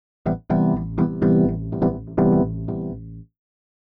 ORGAN025_VOCAL_125_A_SC3(R).wav
1 channel